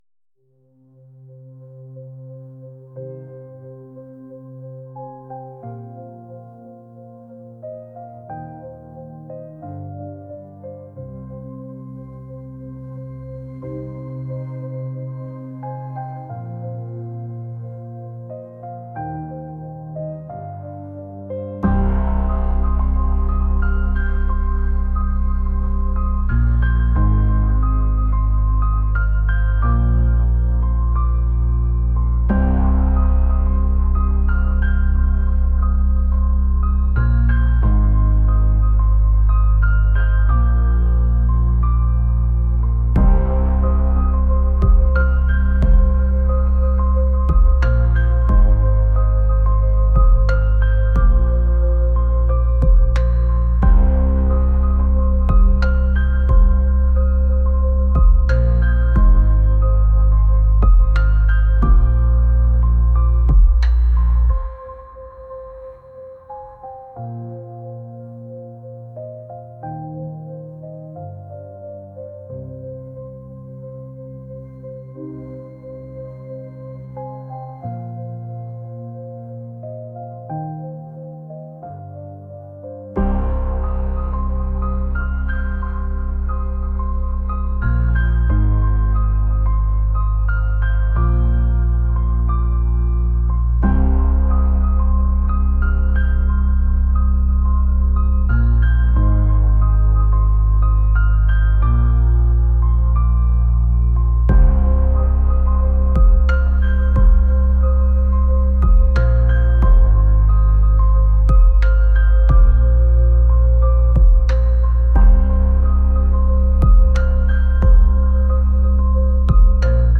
ambient | cinematic | pop